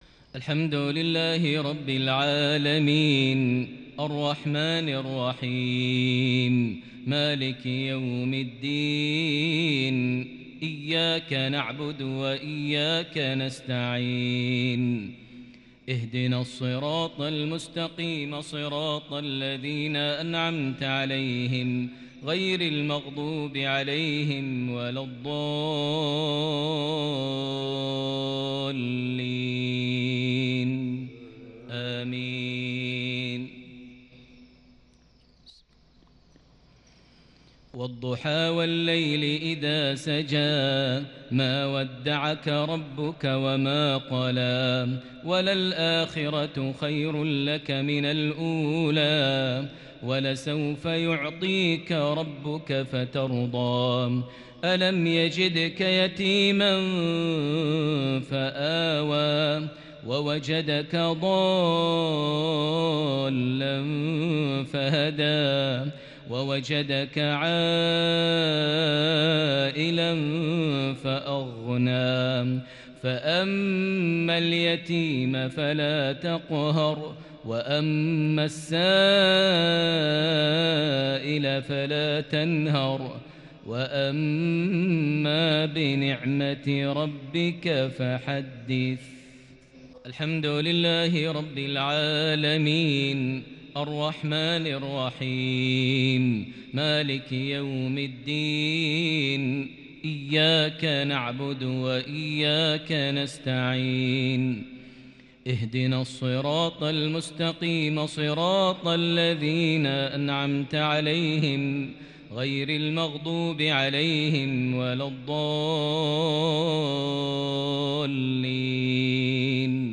مغربية فذه من سورتي الضحى - الشرح | 5 شعبان 1442هـ > 1442 هـ > الفروض - تلاوات ماهر المعيقلي